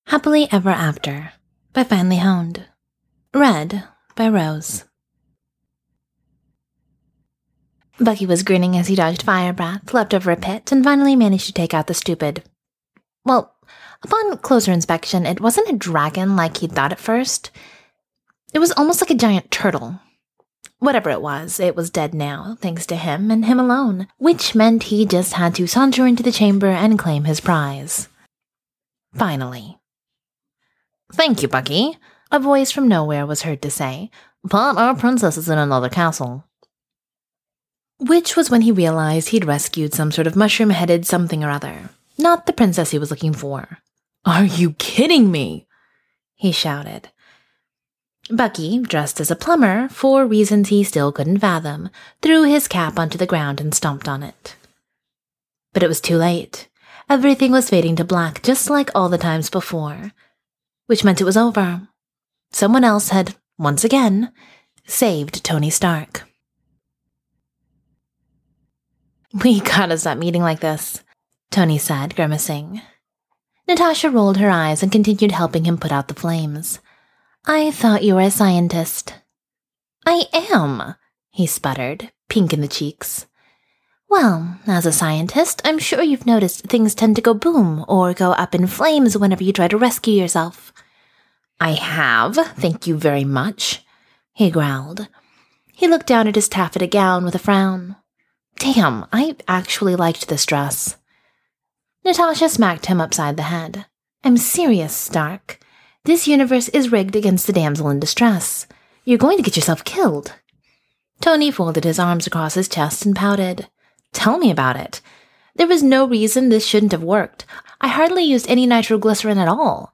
[Podfic] Happily Ever AFter